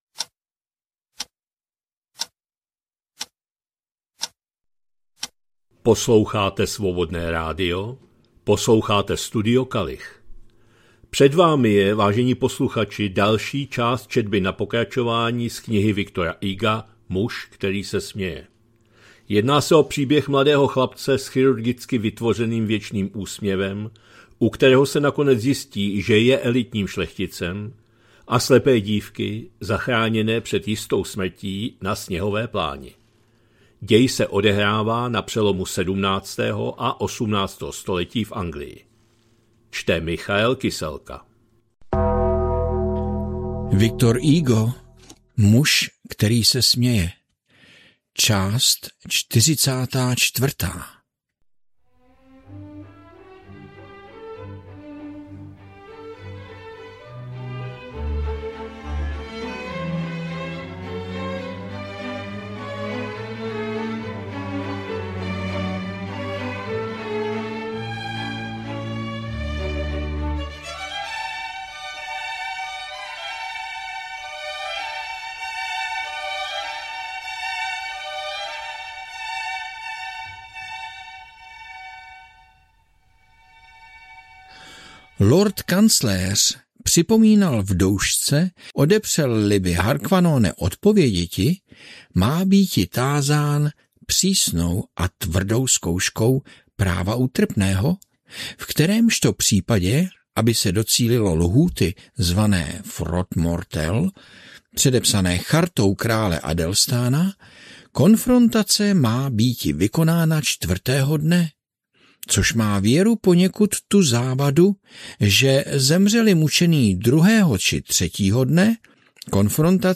2026-01-16 – Studio Kalich – Muž který se směje, V. Hugo, část 44., četba na pokračování